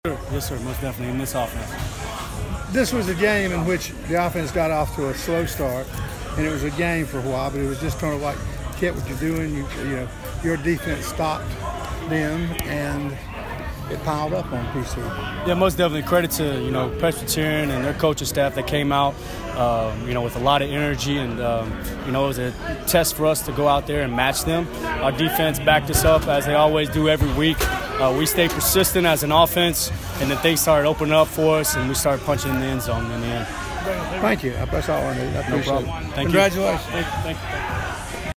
Postgame Audio (Players)